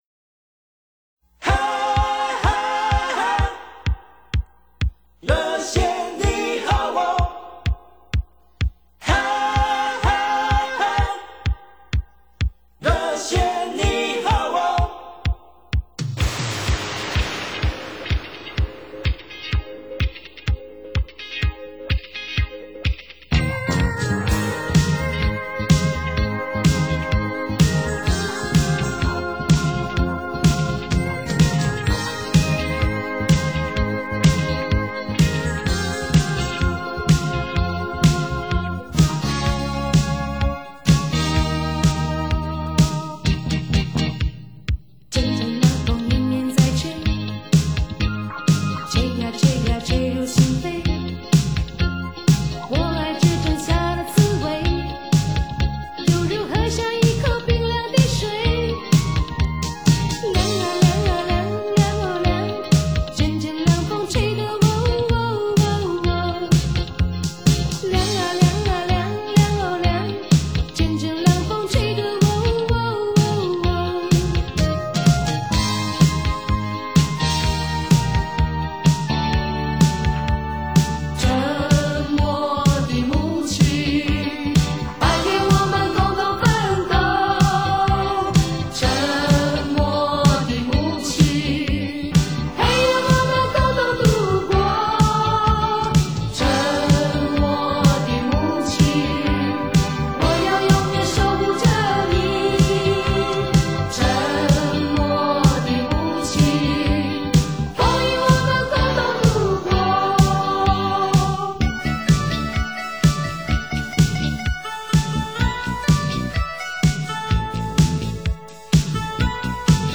采45轉快轉的方式演唱串聯當紅歌曲的組曲